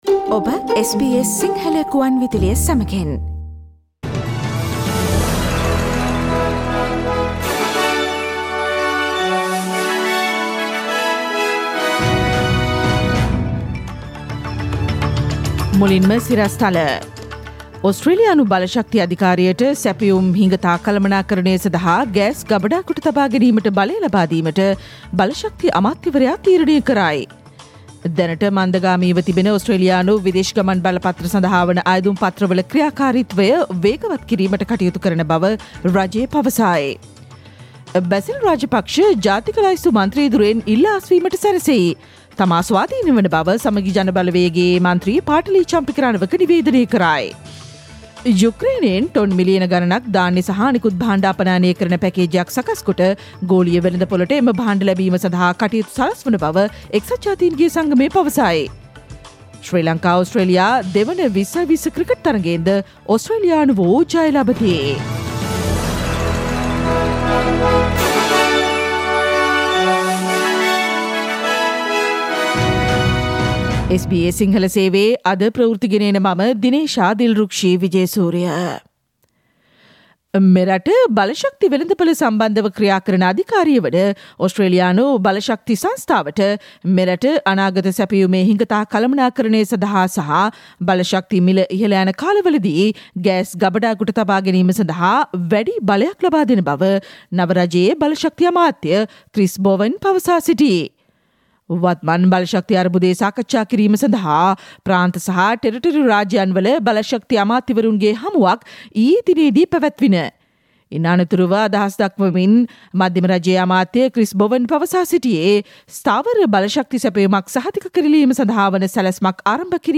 ඉහත චායාරූපය මත ඇති speaker සලකුණ මත ක්ලික් කොට ජූනි 11 දා , බ්‍රහස්පතින්දා SBS සිංහල ගුවන්විදුලි වැඩසටහනේ ප්‍රවෘත්ති ප්‍රකාශයට ඔබට සවන්දිය හැකියි.